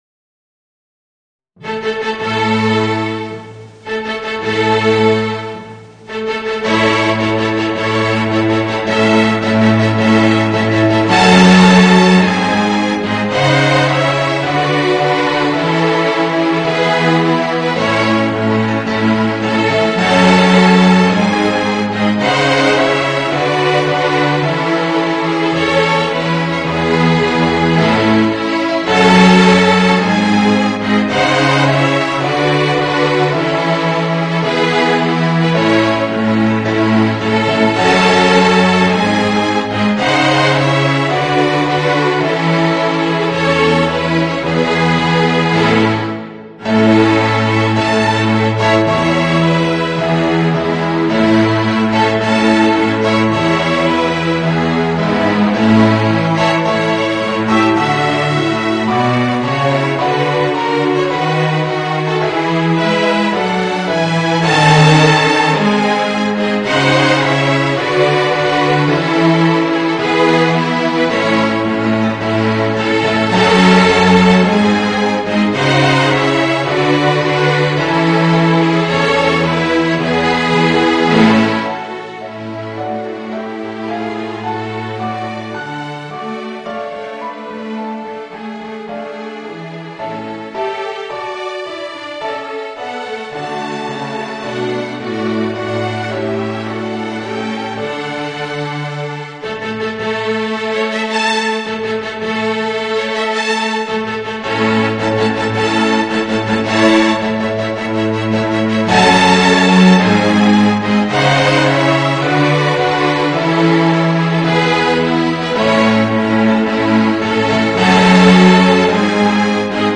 String Orchestra and Organ